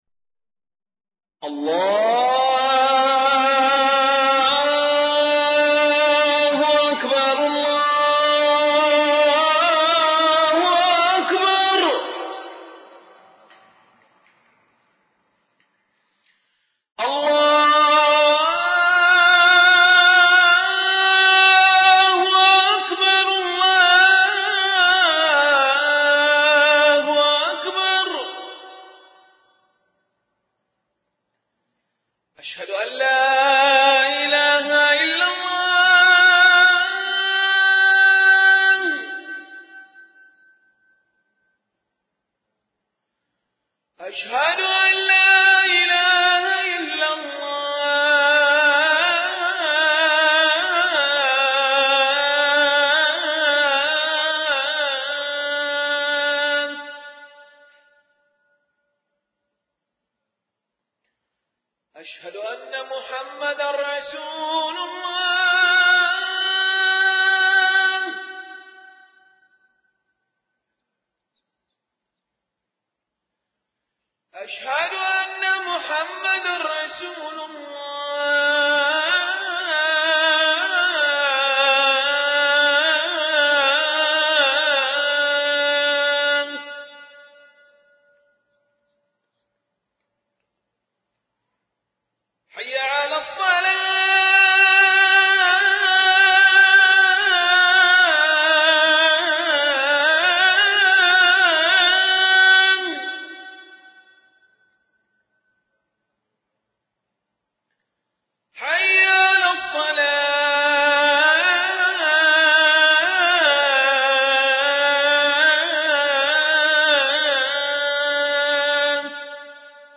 أناشيد ونغمات
عنوان المادة أذان الحرم المكي - 2